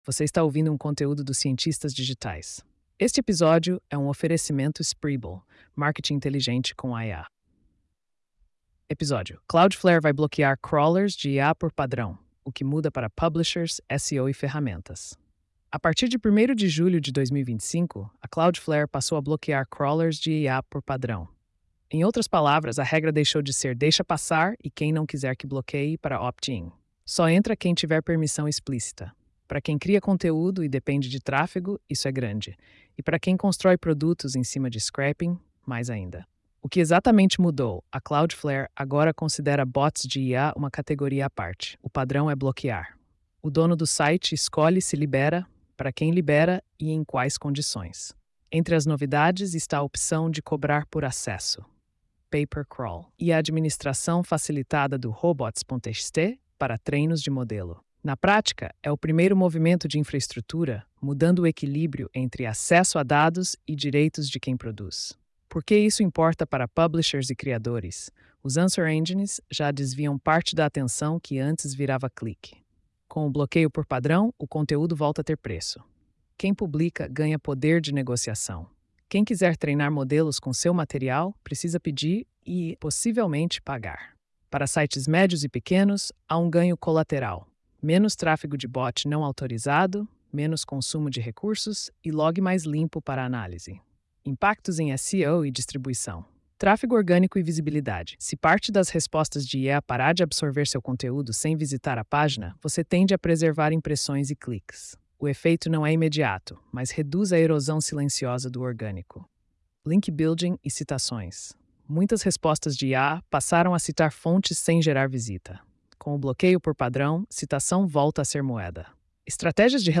post-4278-tts.mp3